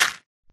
gravel4.ogg